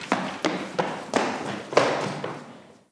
Chico corriendo por un pasillo
Sonidos: Acciones humanas